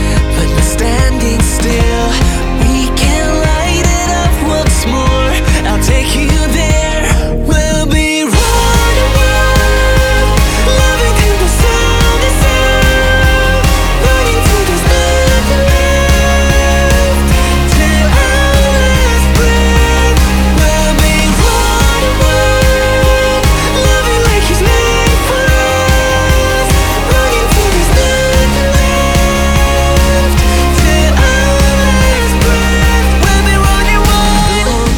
Жанр: Поп / K-pop